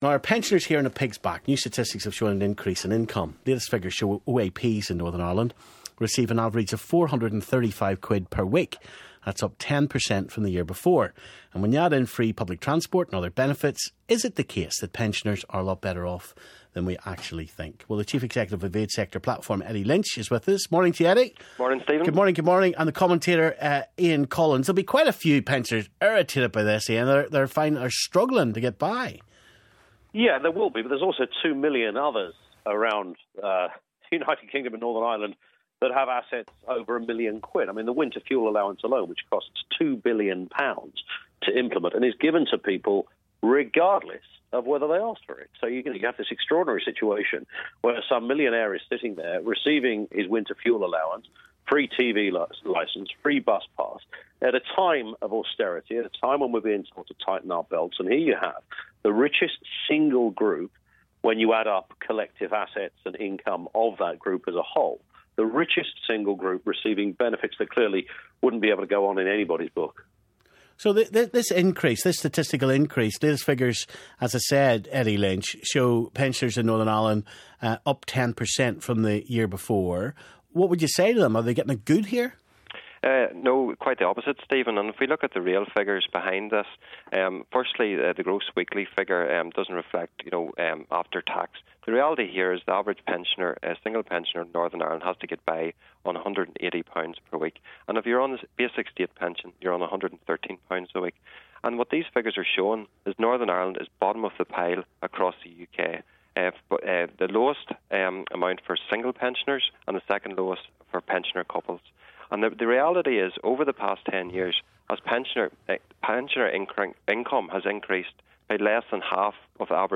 debate.